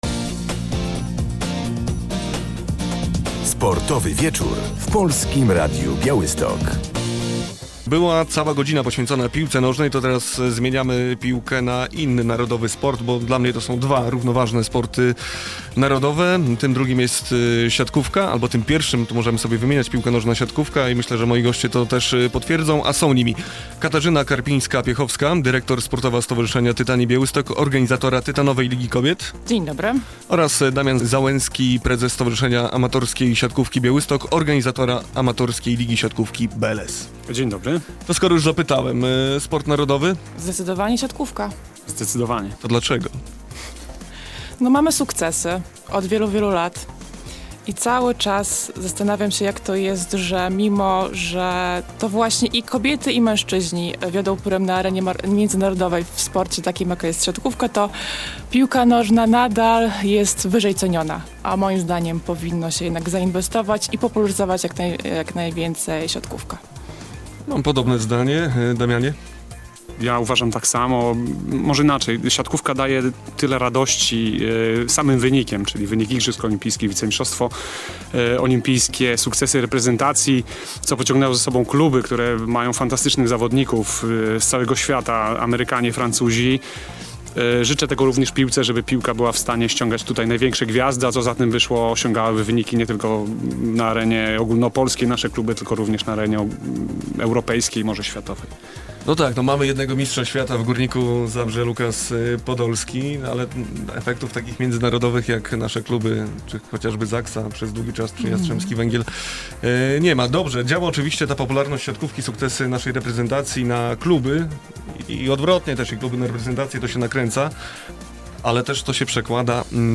Amatorska siatkówka w Białymstoku ma się znakomicie - rozmowa z organizatorami rozgrywek BLS i TLK